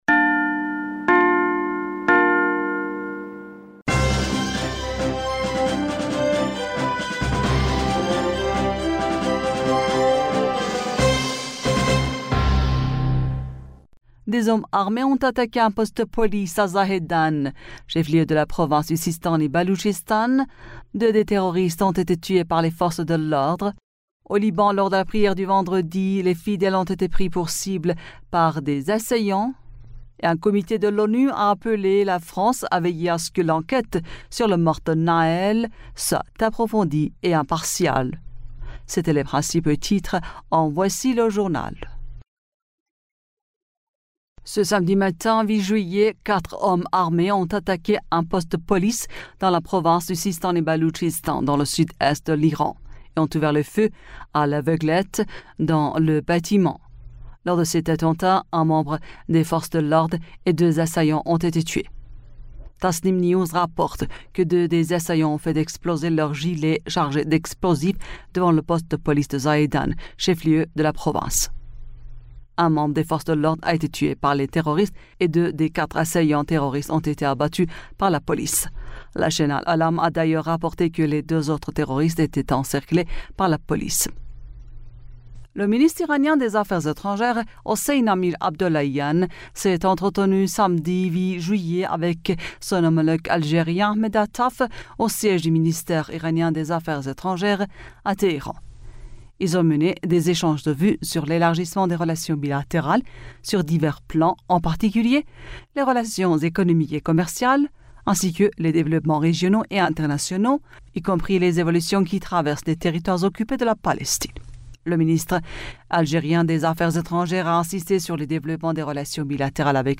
Bulletin d'information du 08 Juillet 2023